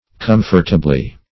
Comfortably \Com"fort*a*bly\, adv.